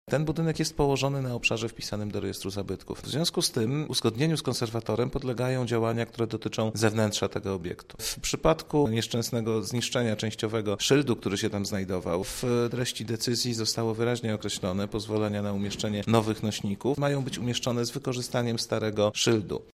– wyjaśnia Hubert Mącik, Miejski Konserwator Zabytków.